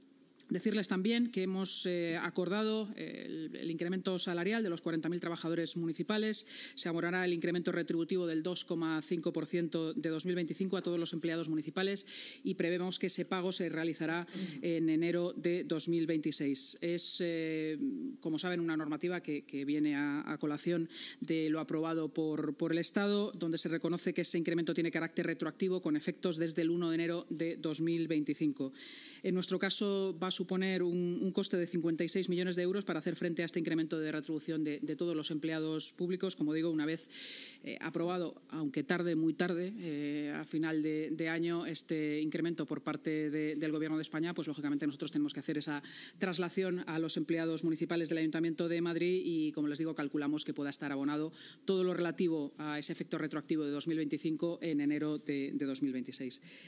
Así lo ha anunciado la vicealcaldesa y portavoz municipal, Inma Sanz, en la rueda de prensa posterior a la Junta.